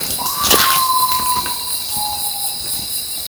Urutaú Común (Nyctibius griseus)
Nombre en inglés: Common Potoo
Localidad o área protegida: Parque Nacional Iguazú
Condición: Silvestre
Certeza: Vocalización Grabada
Urutau.mp3